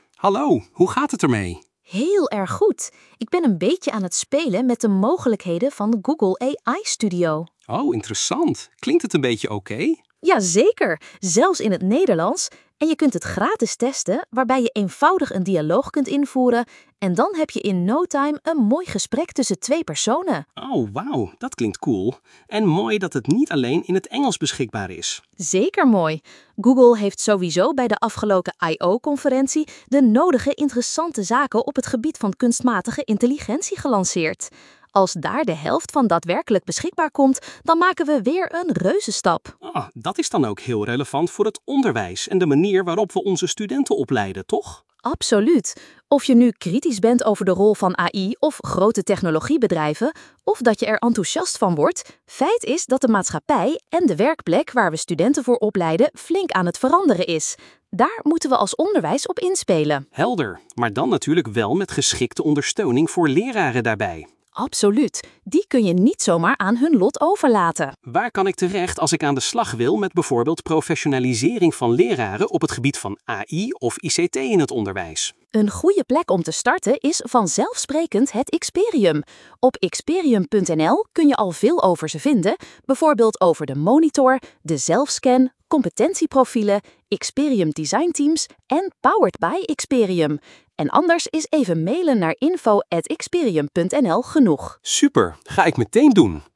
Gesprek tussen 2 personen in het Nederlands via tekst naar spraak
Dat lijkt nu in AI Studio beter te gaan:
dialoog-iXperium.mp3